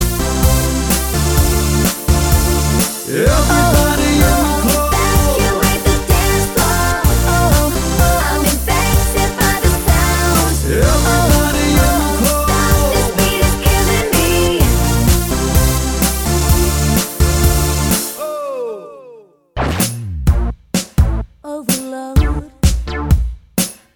Duet Dance 3:25 Buy £1.50